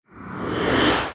archer_skill_spyraledge_02_charge.ogg